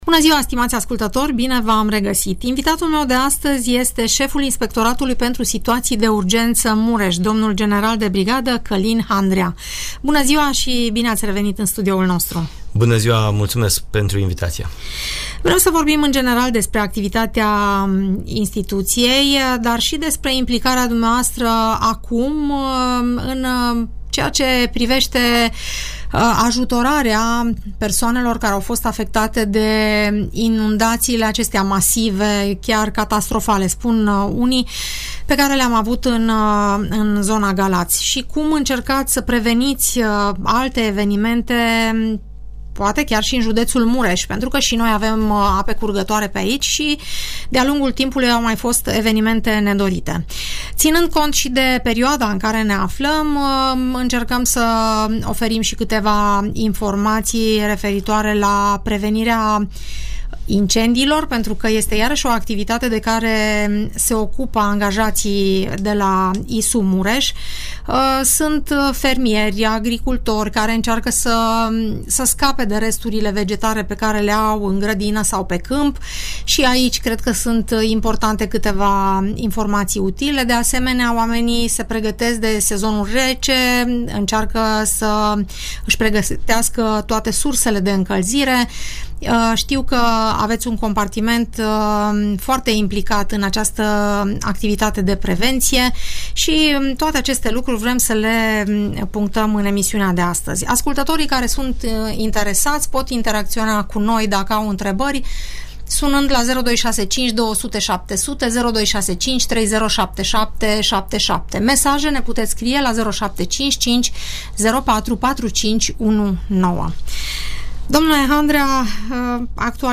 Șeful Inspectoratului pentru Situații de Urgență Mureș, dl general de brigadă Călin Handrea, vorbește la Radio Tg. Mureș, despre activitatea curentă a instituției, evenimentele nedorite la care pompierii sau paramedicii au intervenit și despre ceea ce putem face pentru a le evita.